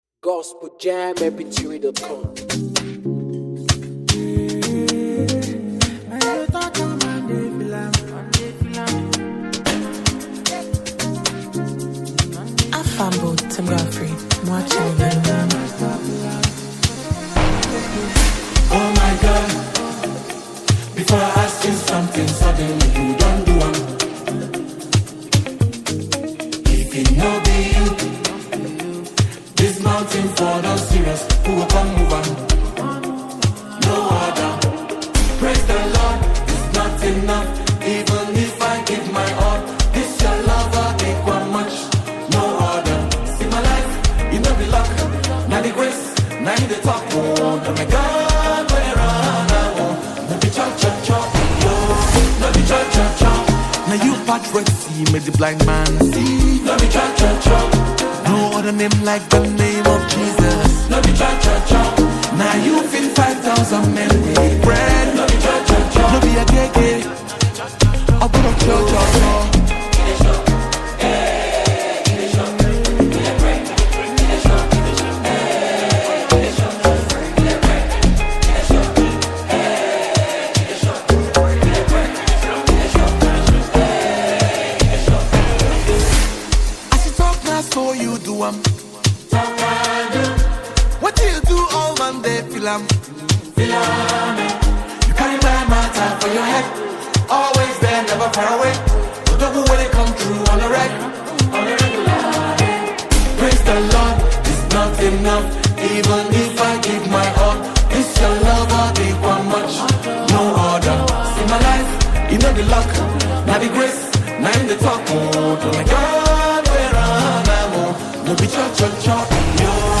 energetic Afrobeat rhythms with uplifting lyrics